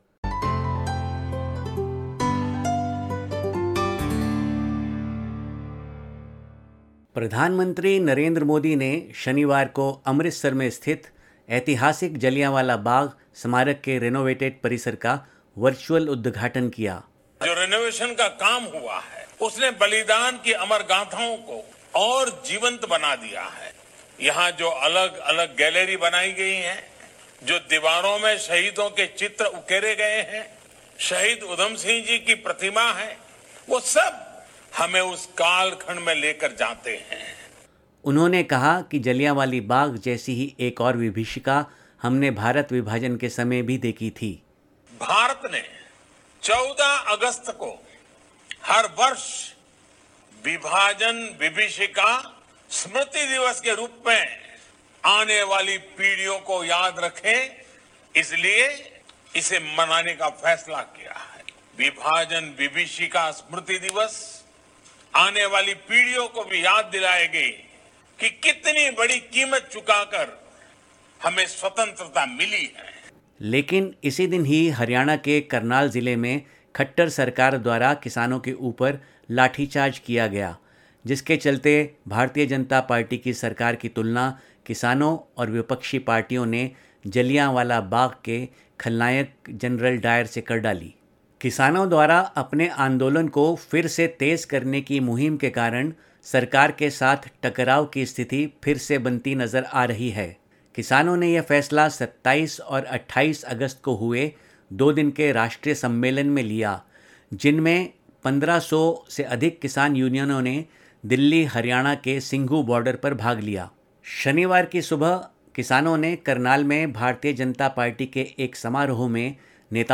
august_30_hindi_news_bulletin_0.mp3